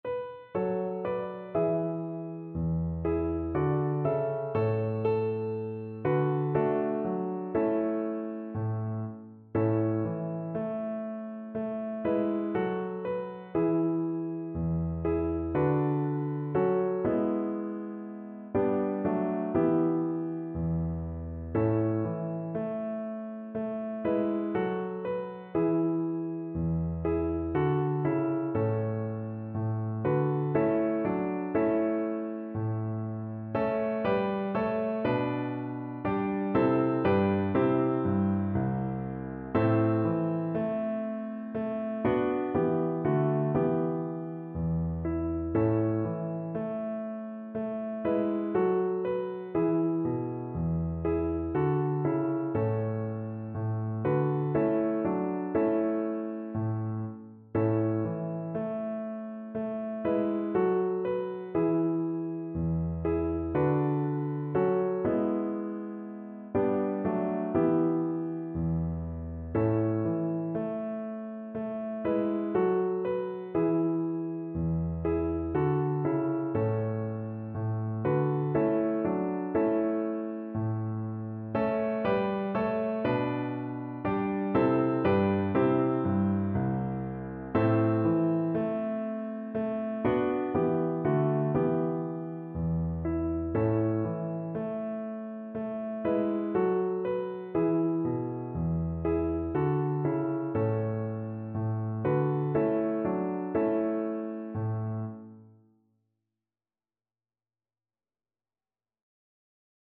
Christmas Christmas Violin Sheet Music The Wexford Carol
Violin
Traditional Music of unknown author.
3/4 (View more 3/4 Music)
A major (Sounding Pitch) (View more A major Music for Violin )
Slow, expressive =c.60